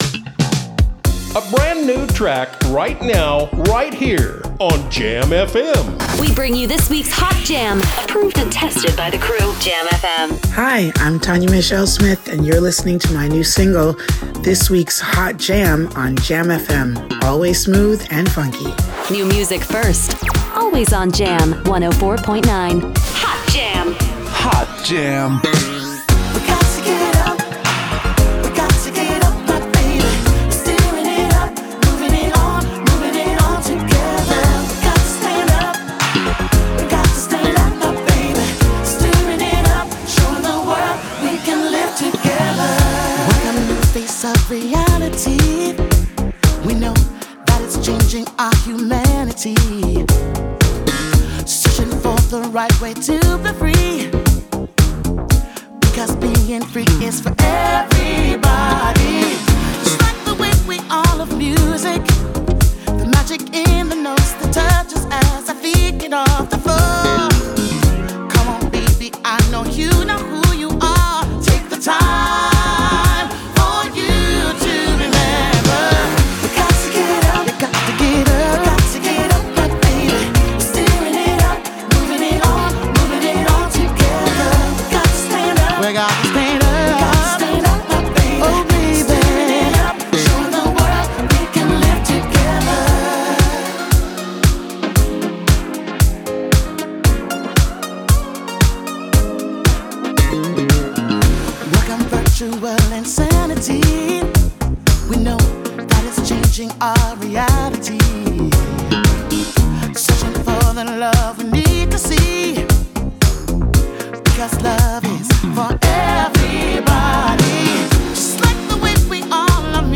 Disco-Funk
krachtige en soulvolle stem
frisse interpretatie van het klassieke disco-funkgeluid